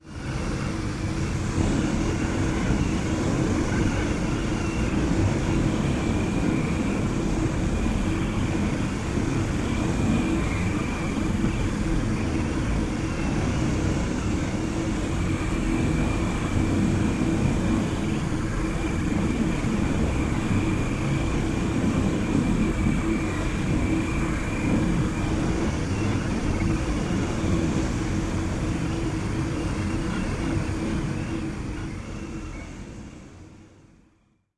Звук вентиляции, которая сильно затягивает воздух